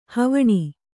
♪ havaṇi